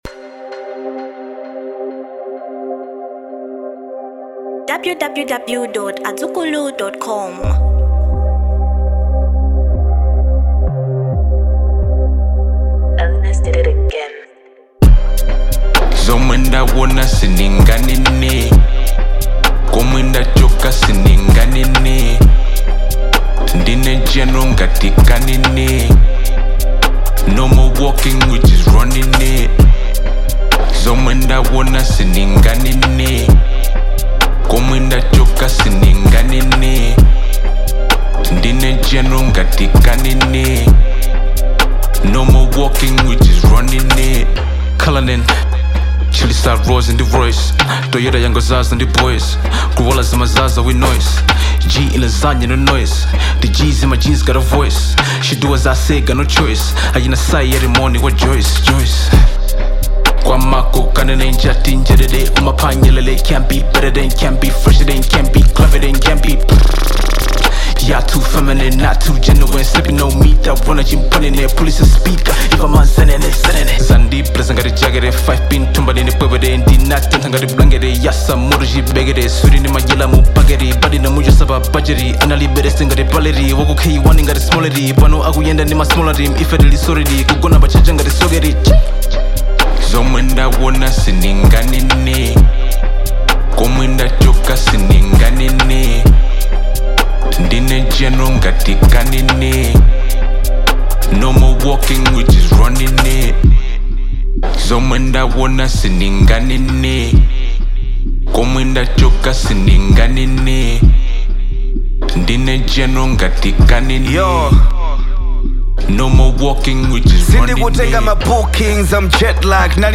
Genre Hip-hop